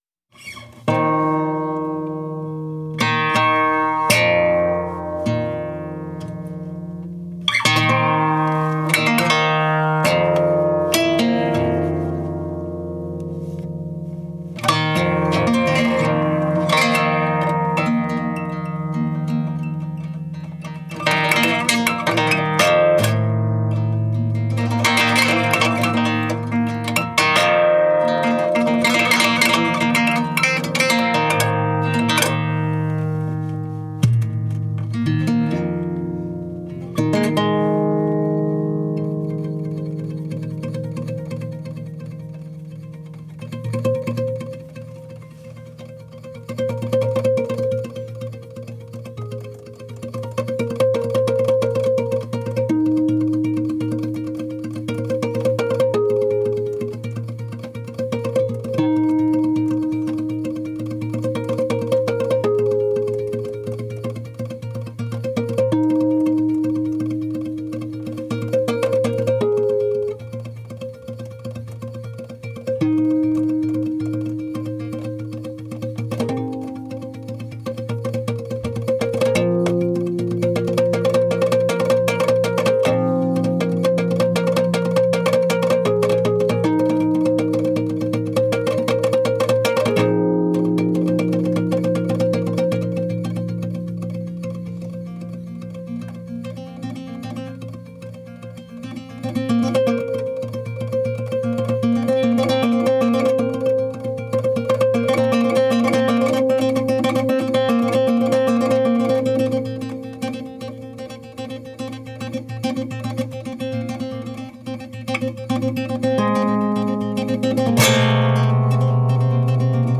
ブータン人ギタリスト